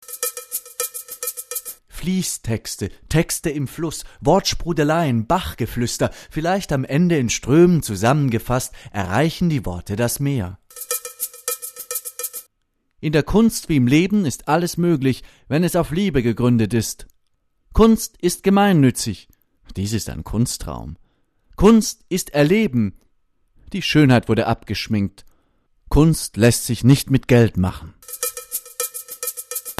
Sprecherdemos